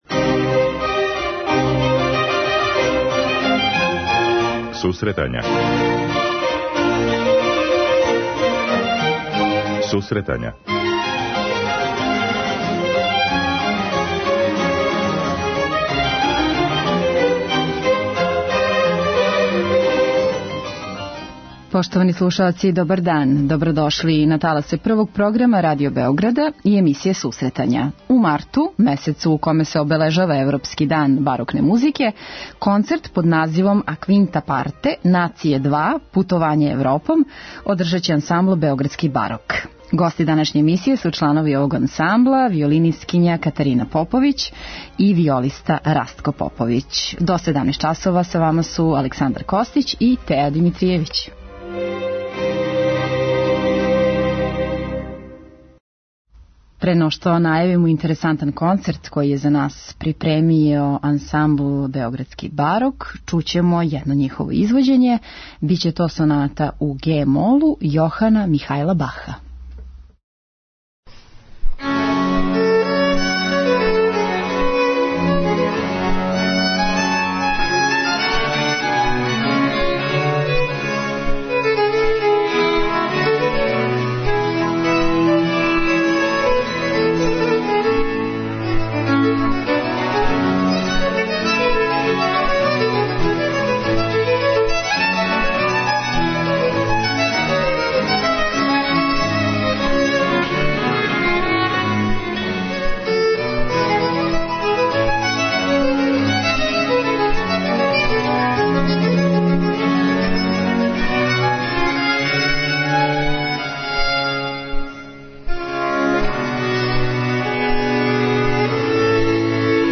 преузми : 10.60 MB Сусретања Autor: Музичка редакција Емисија за оне који воле уметничку музику.